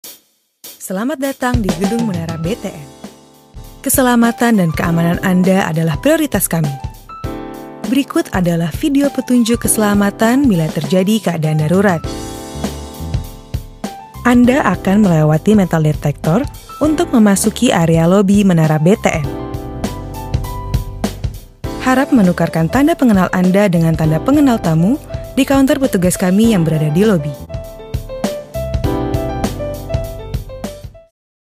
Powerful yet Kind. Dignified but approachable.